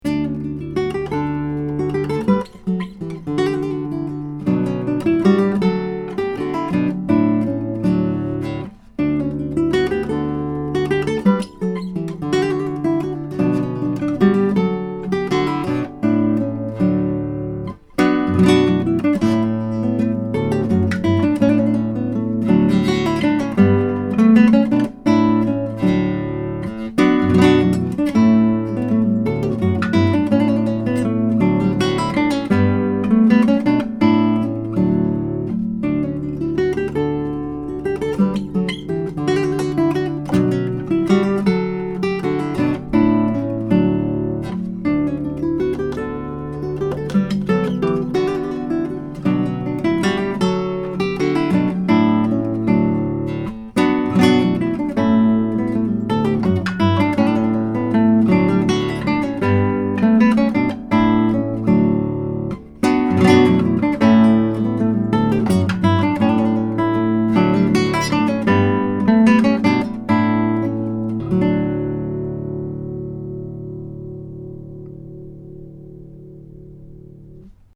10-String Guitar
The guitar has amazing sympathetic resonance and sustain, as well as good power and projection, beautiful bass responce, a very even response across the registers. These MP3 files have no compression, EQ or reverb -- just straight signal, tracked through a Wunder CM7GT multi-pattern tube mic, into a Presonus ADL 600 preamp into a Rosetta 200 A/D converter.
12 | Bianca Fiore (Negri., Italian Renaissance)